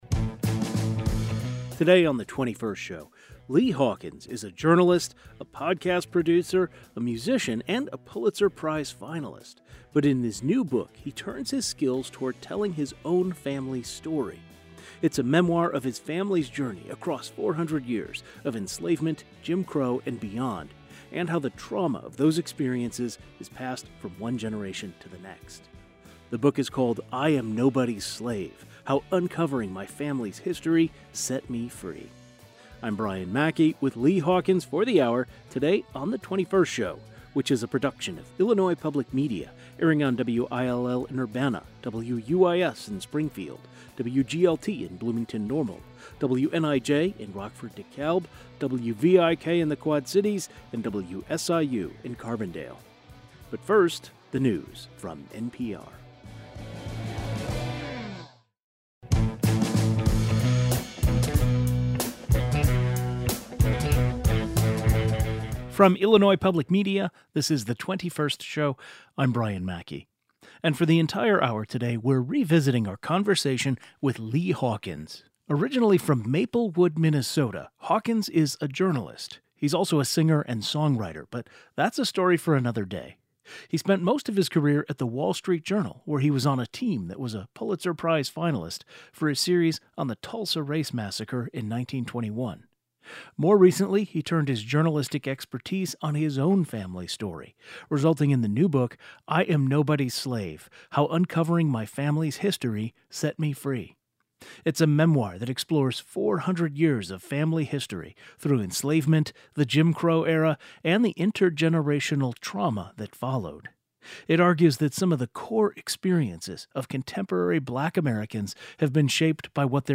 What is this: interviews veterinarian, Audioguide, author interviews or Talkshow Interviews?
author interviews